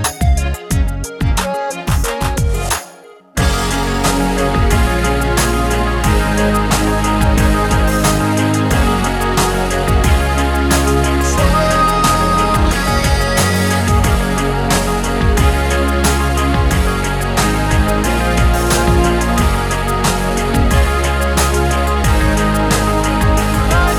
Two Semitones Down Pop (2010s) 3:14 Buy £1.50